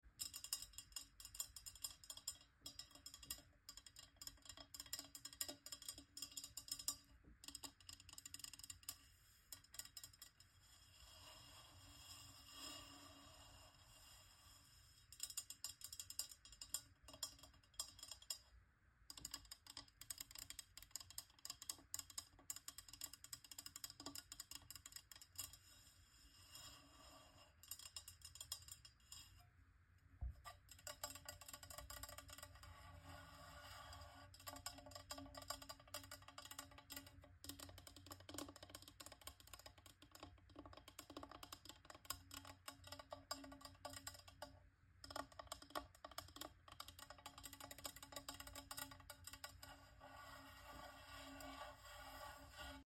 Water bottle tinglies! You’re halfway